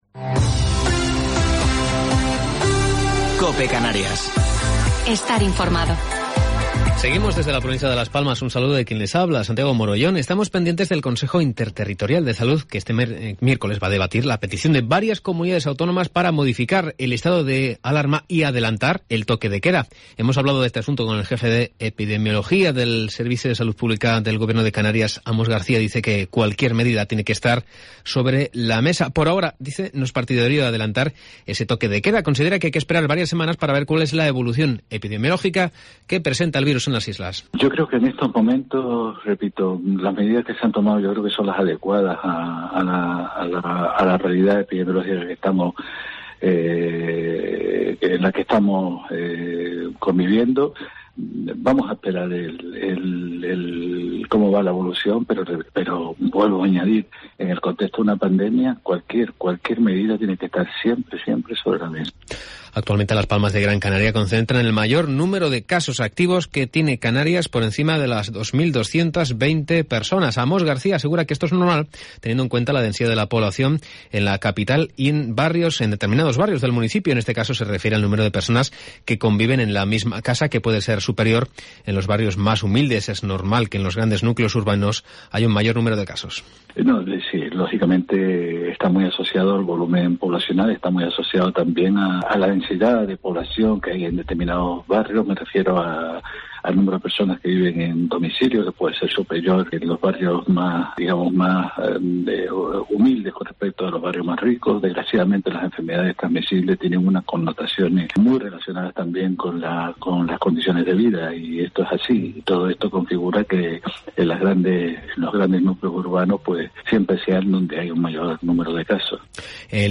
Informativo local 20 de Enero del 2021